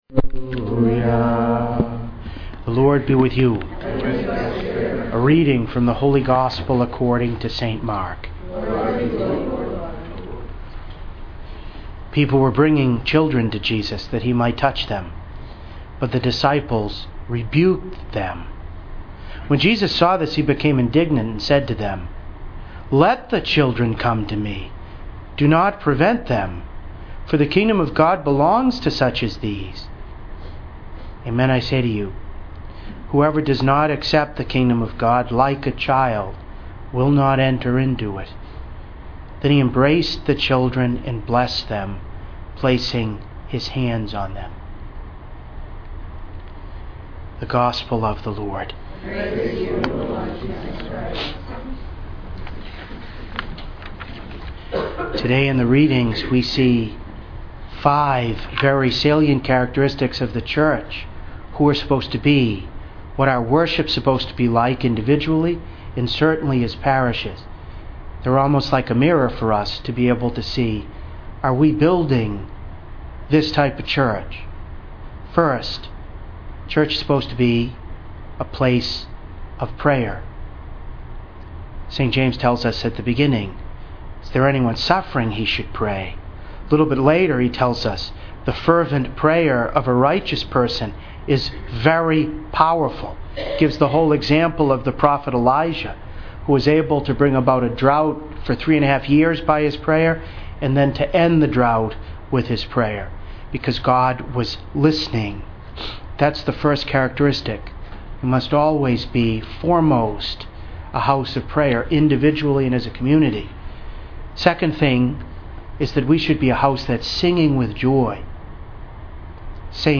Saturday of the Seventh Week in Ordinary Time, Year II Votive Mass of Our Lady, Health of the Sick
3.1.14-Homily-1.mp3